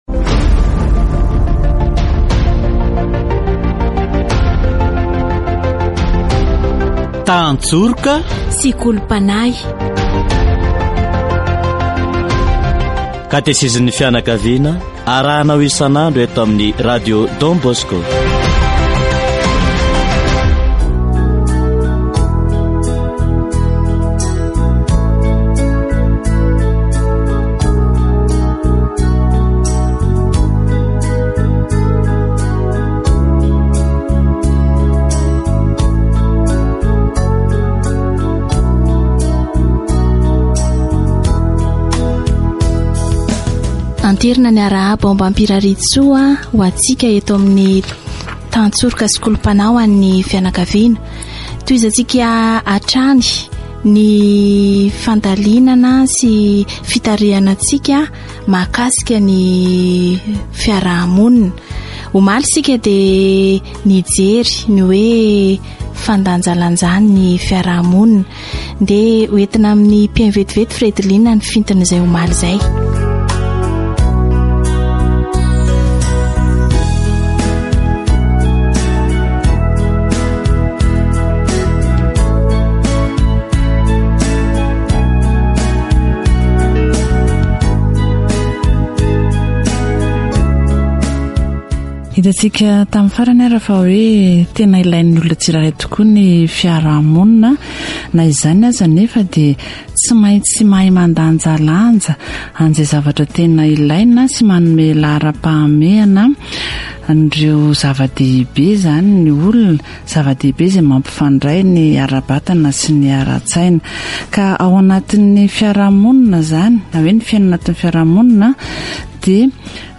Catechesis on Society according to God's design